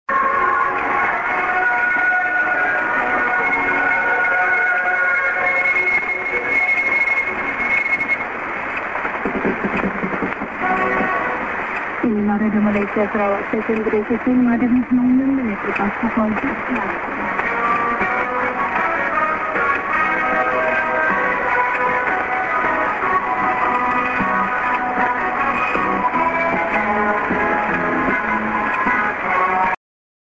music->ID(women)->music